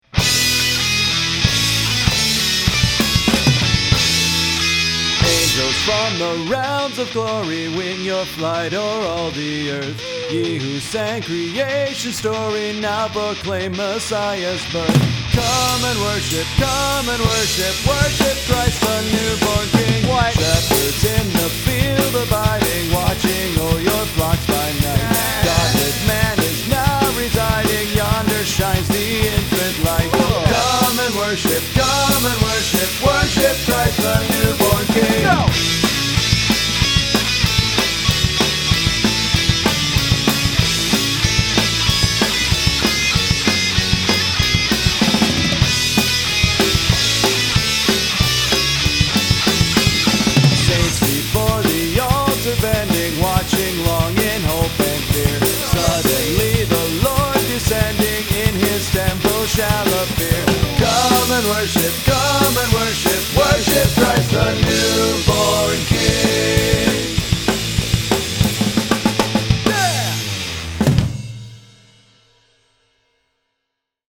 But, since we can’t just ignore traditions, all of mine are in place – the “way too fast” song, the harmonized guitar lead, “holy crap”, and even that awesome crashing sound.
Recorded and mixed at The House, Rochester, NY, Nov-Dec 2013.